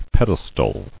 [ped·es·tal]